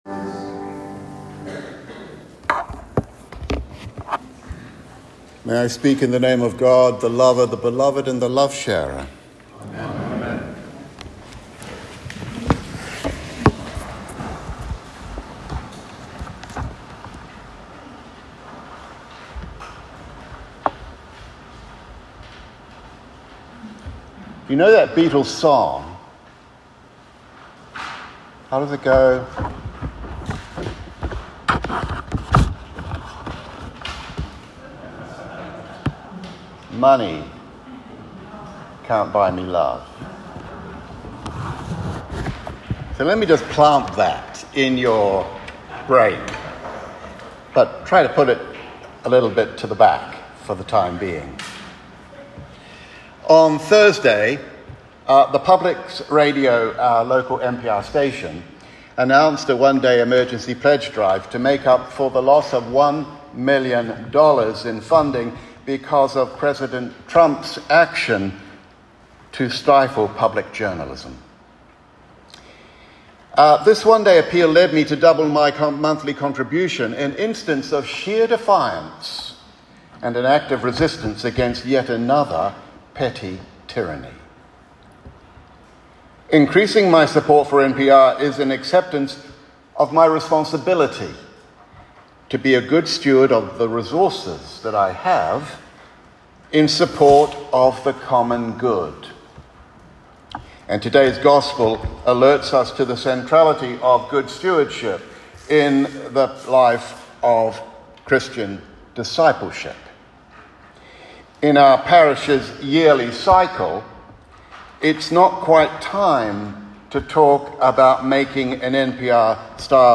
Sermon Recording: